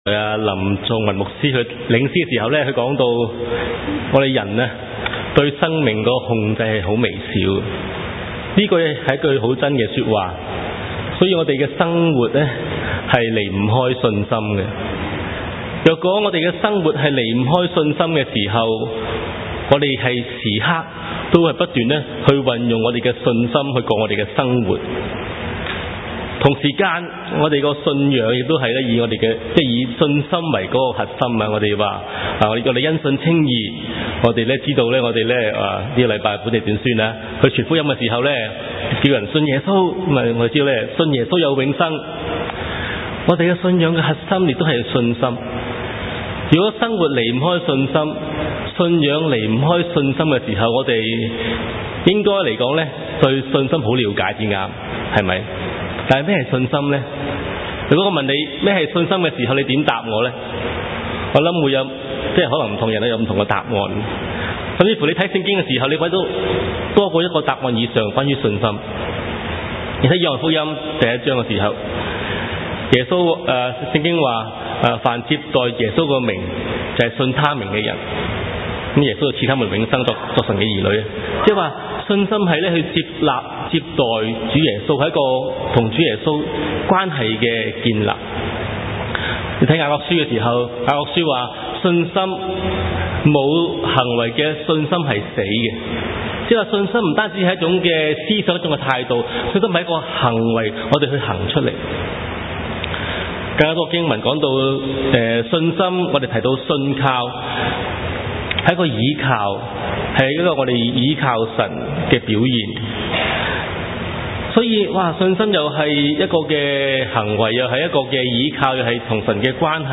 牛頓粵語崇拜 , 講道